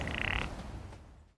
Здесь вы можете слушать и скачивать их естественные голоса: от блеяния молодых особей до предупредительных сигналов взрослых.
Голос альпийской серны